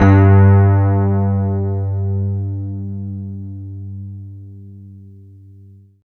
47 RHOD G2-R.wav